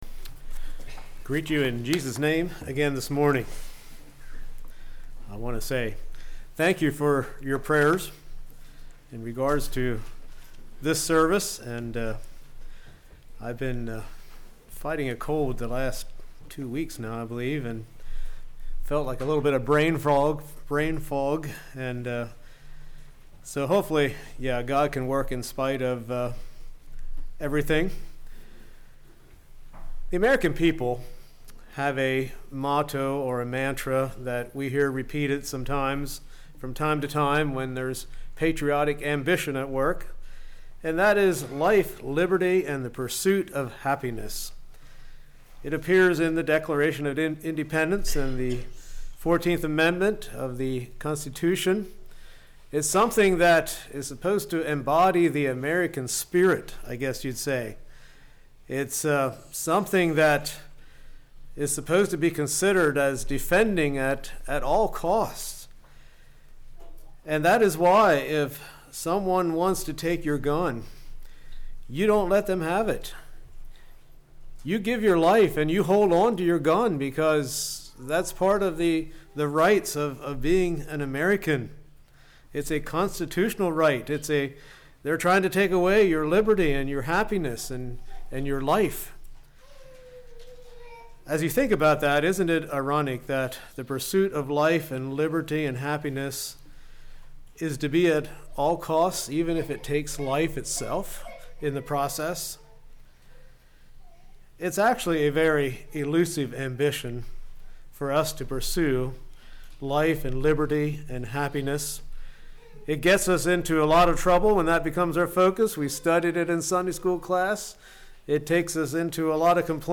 Sermons
Hidden River | All Day Meetings 2025